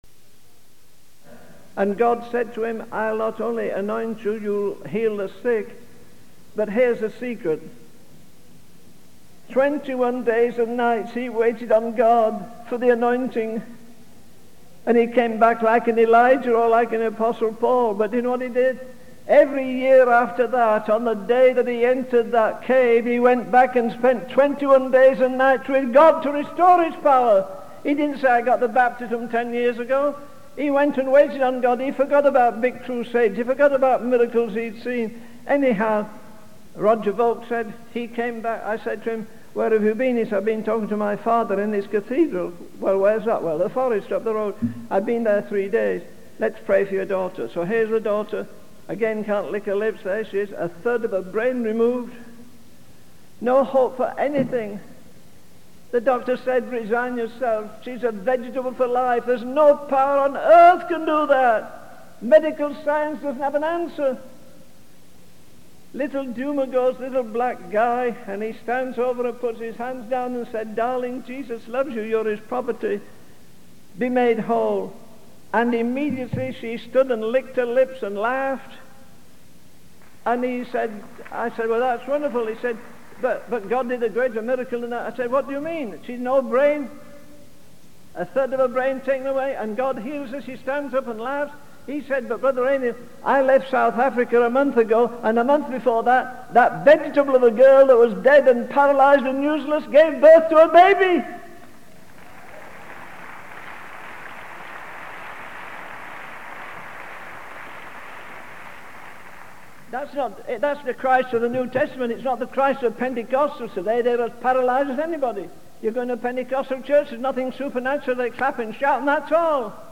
In this sermon, the speaker emphasizes the impact of one person's obedience to God. He uses examples of individuals who, despite their limitations, made a significant impact through their faithfulness to God's calling.